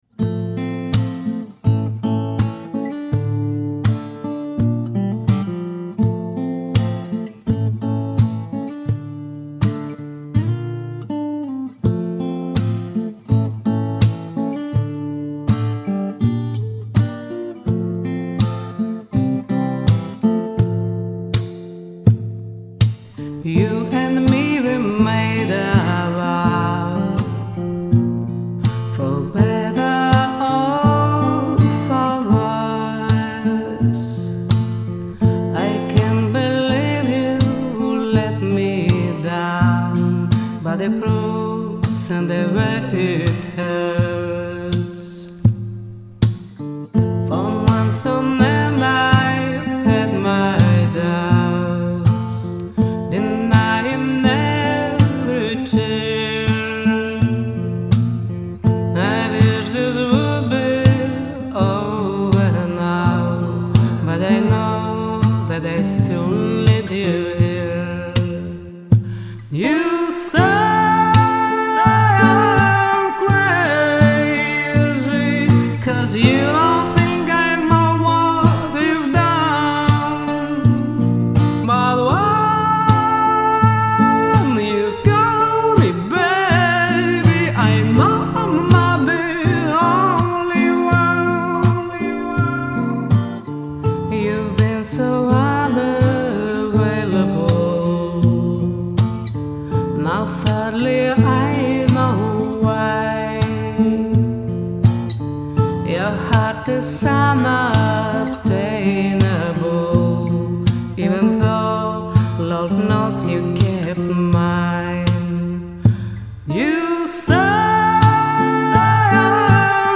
Девчонки - у обеих косяки в интонировании.